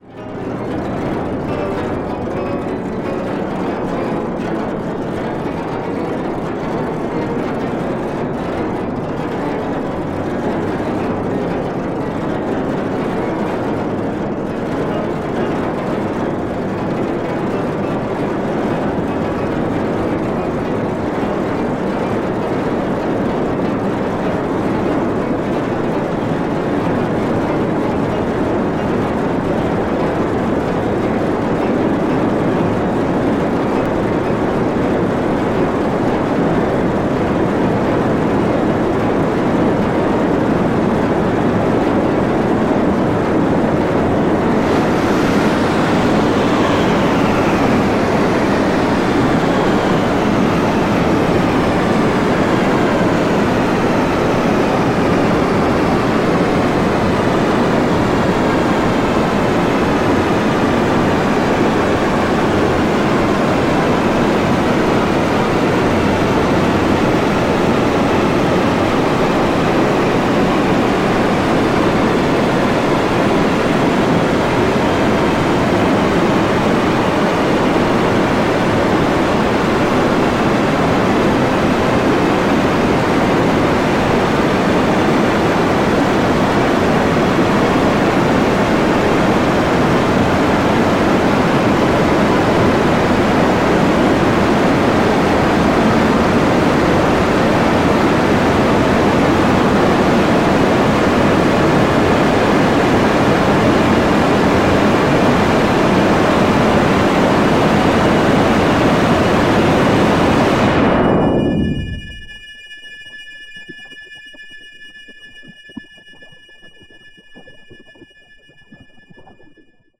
Electroacoustic composition in four parts 2014-15